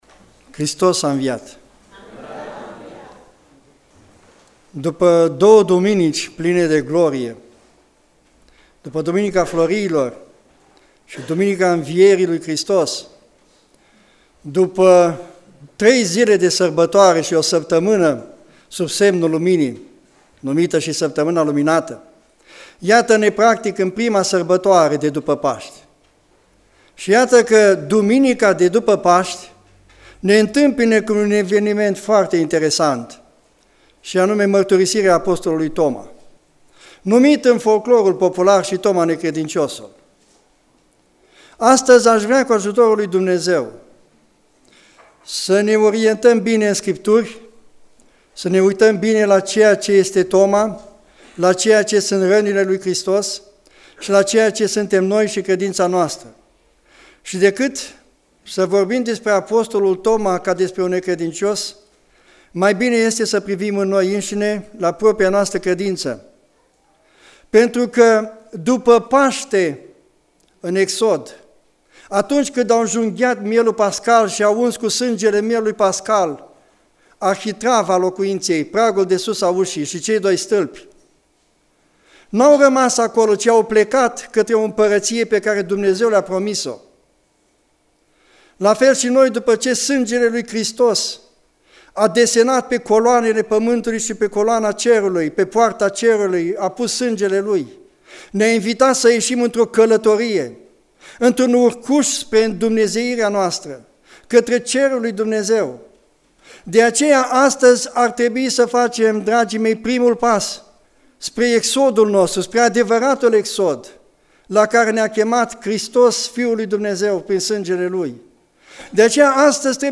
Exegeza Duminica Tomii